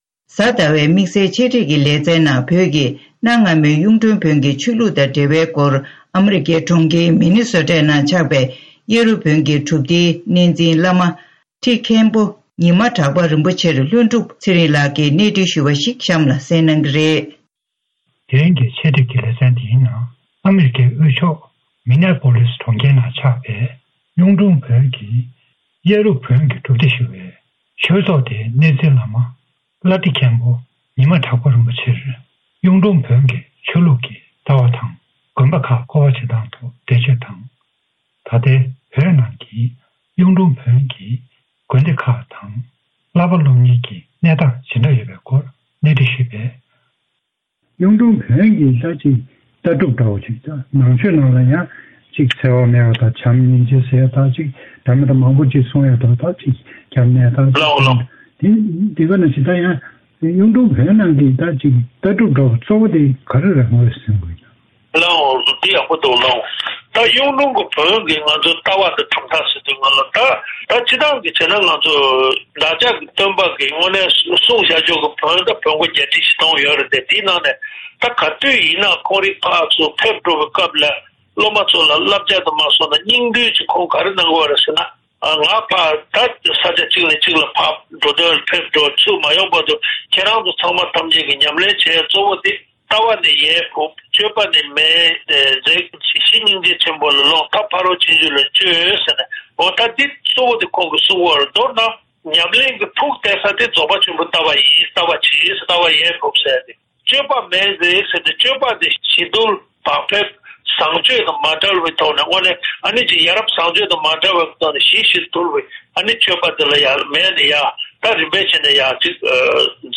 བོད་ཀྱི་གནའ་སྔ་མོའི་གཡུང་དྲུང་བོན་གྱི་ཆོས་ལུགས་སྐོར་གནས་འདྲི་ཞུས་བའི་ཆེད་སྒྲིག་ལེ་ཚན།